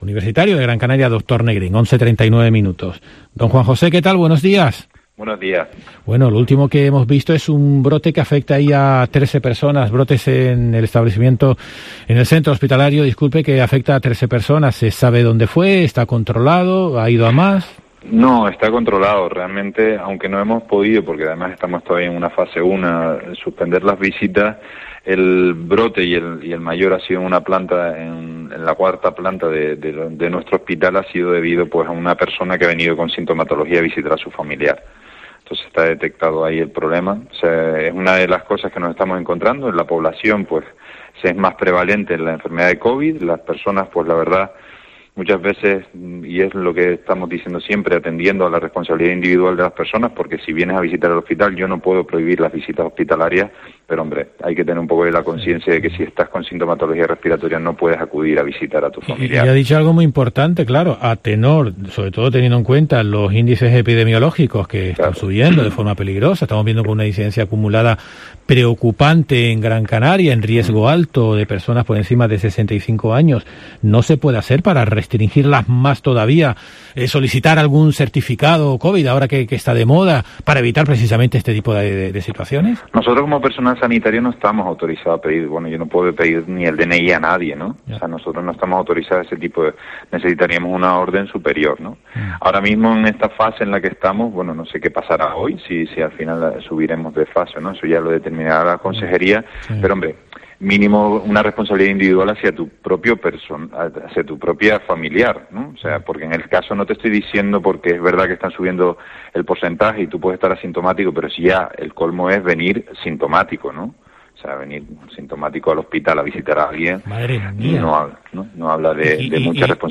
Entrevista completa